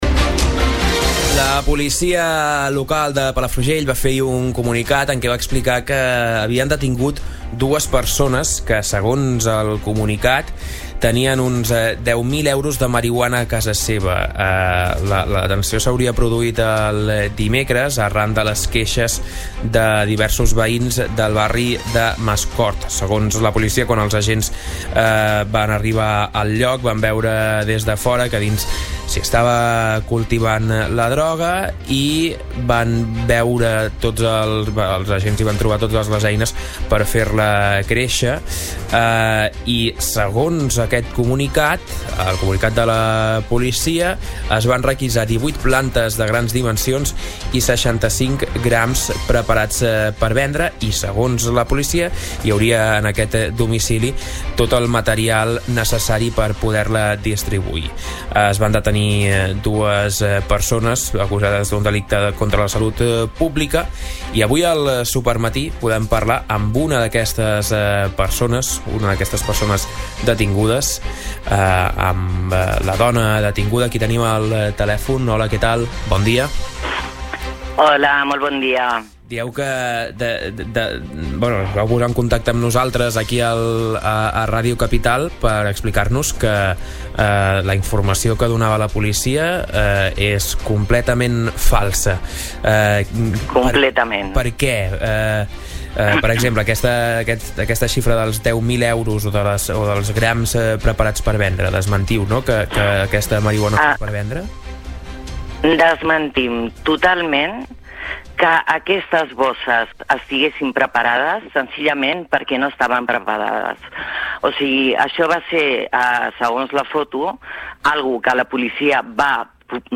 En una entrevista exclusiva a Ràdio Capital, la dona detinguda explica la seva versió de la detenció. Diu que la droga era per a consum propi, i que denunciarà la forma com la Policia Local va fer la detenció